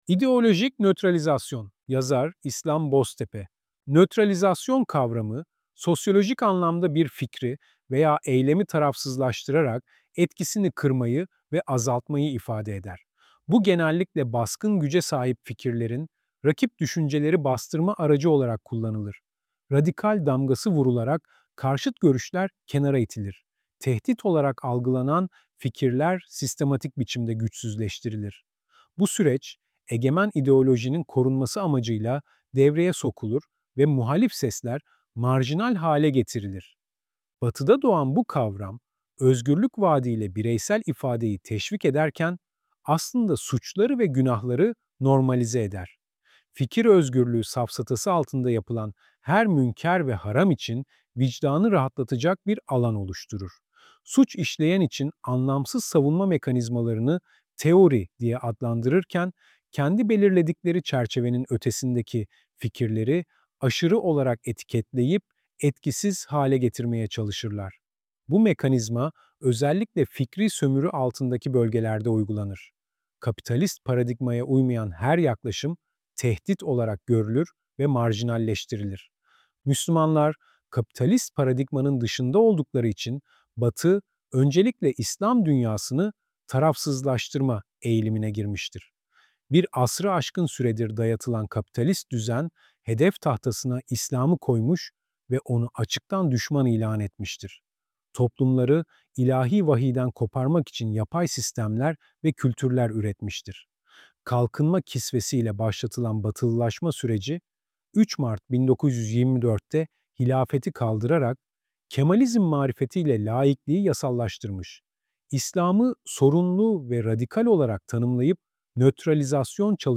Bu ses yapay zeka tarafından oluşturulmuştur